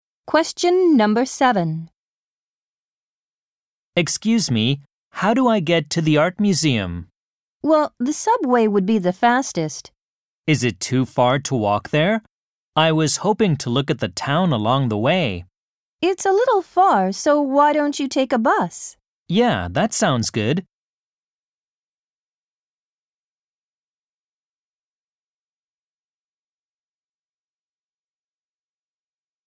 ○共通テストの出題音声の大半を占める米英の話者の発話に慣れることを第一と考え，音声はアメリカ（北米）英語とイギリス英語で収録。
第2問形式：【第2回】第1問　問2 （イギリス英語）